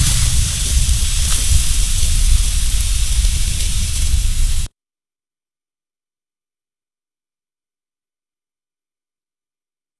Звук камина:
fire9.wav